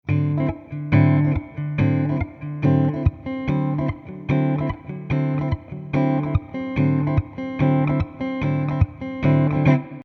Here are some samples of a D9 Chord with the root at the D note on the 5th fret.
D9 Chord Sound Sample 2
D9th Funk Chord
d9thclean.mp3